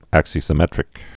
(ăksē-sĭ-mĕtrĭk) also ax·i·sym·met·ri·cal (-rĭ-kəl)